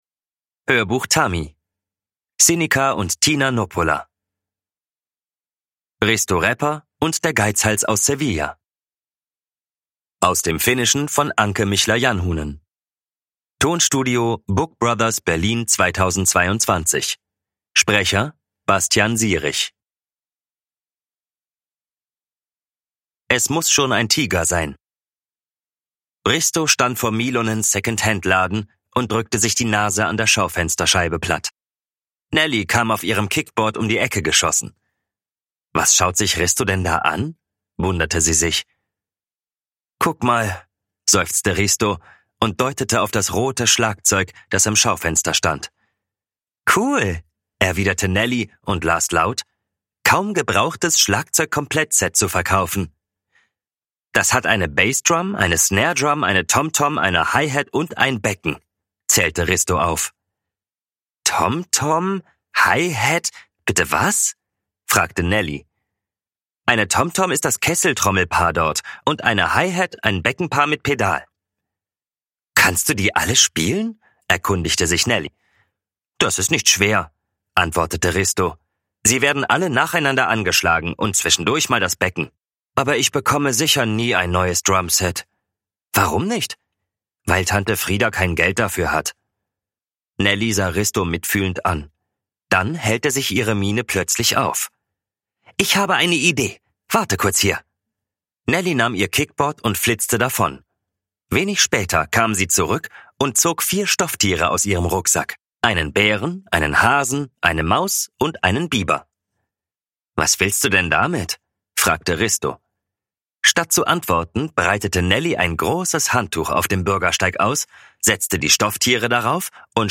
Risto Räpper und der Geizhals aus Sevilla – Ljudbok – Laddas ner